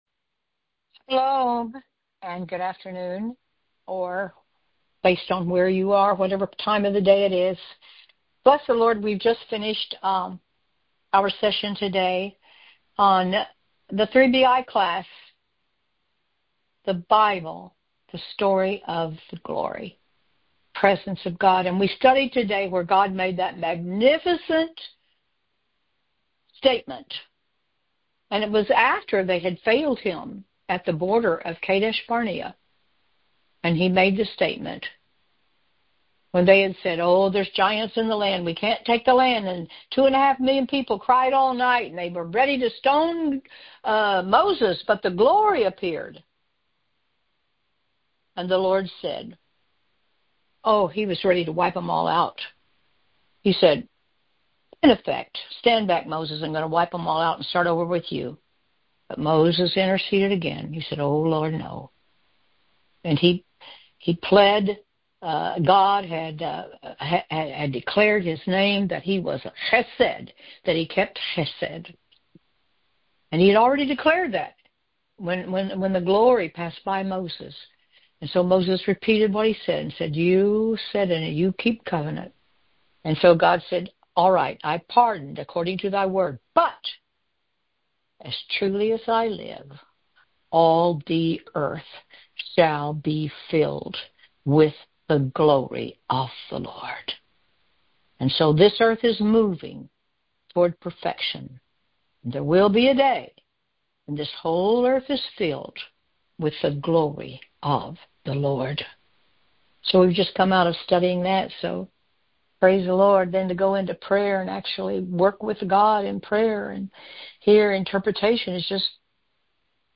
Wednesday Noon Prayer
The audio was recorded via our BBM Phone Cast system.